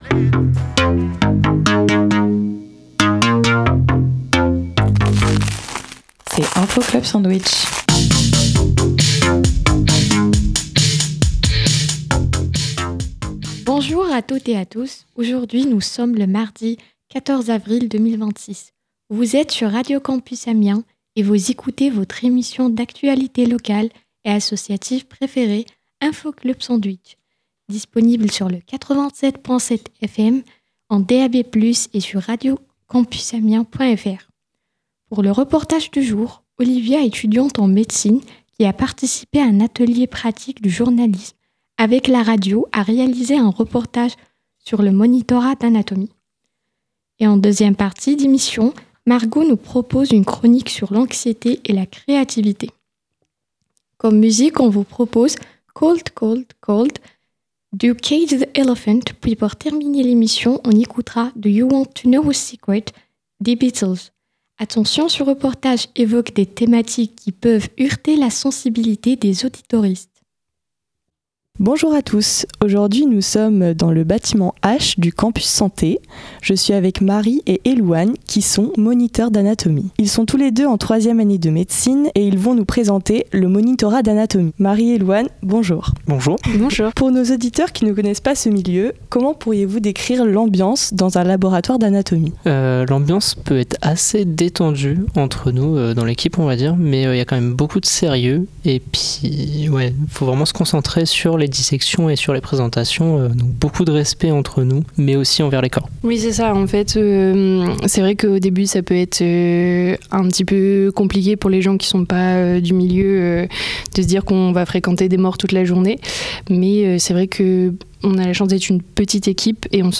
Attention, l’interview parle notamment de mort et de dissections.